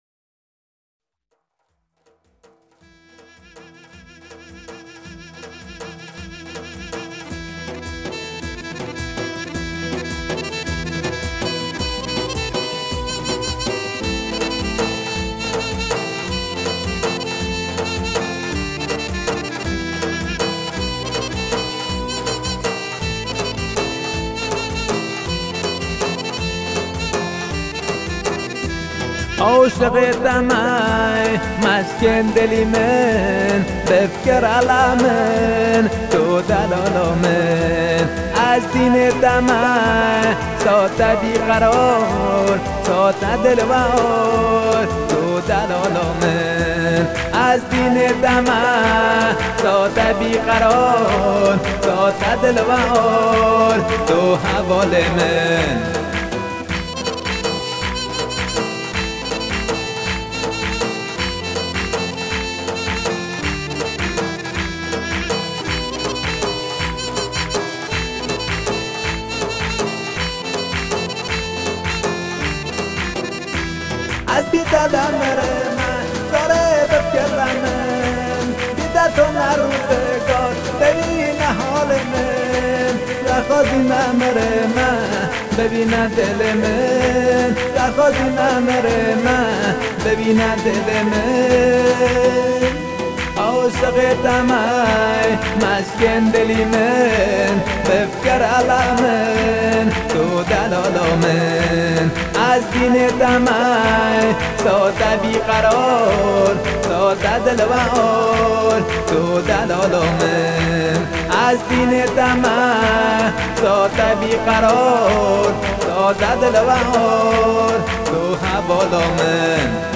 کیبوردی محلی
آهنگ کرمانجی